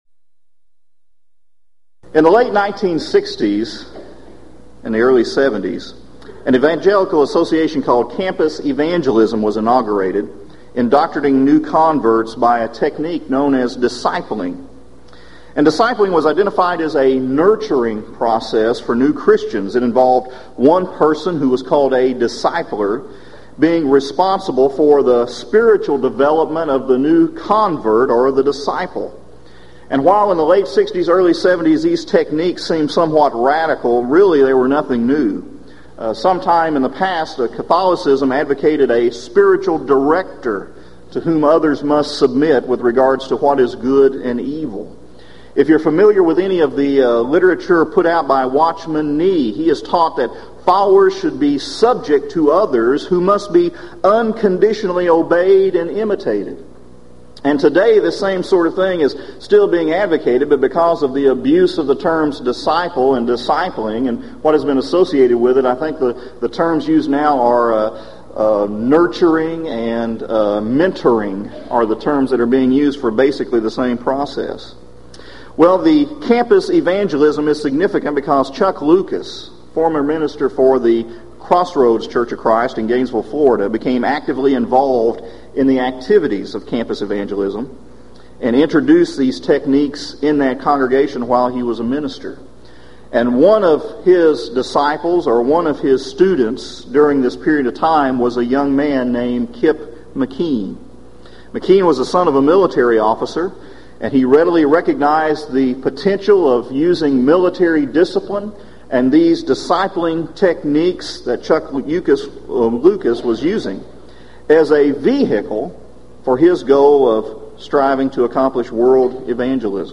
Event: 1996 Gulf Coast Lectures
this lecture